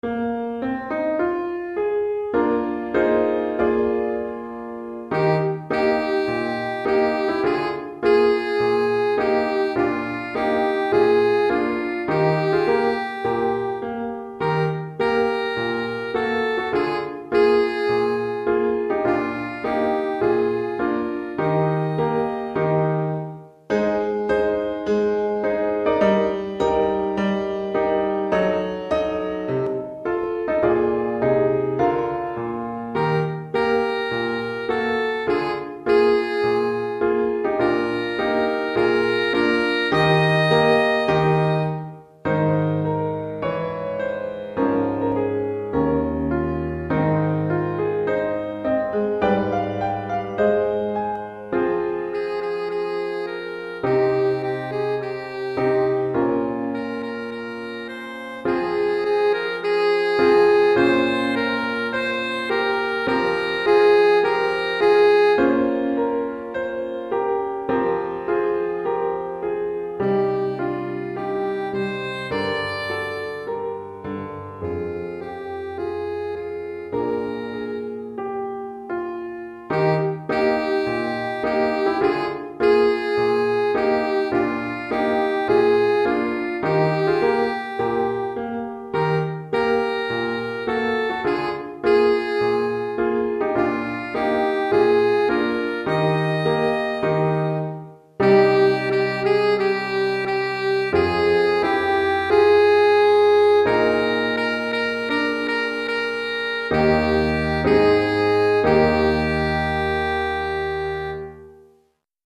Pour saxophone alto et piano DEGRE CYCLE 1